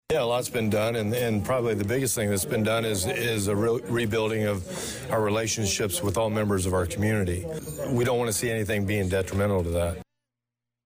After speaking before the Danville City Council Tuesday night, Police Chief Christopher Yates was adamant that significant progress have been made over the last five years to improve relations between Danville Police and African American residents.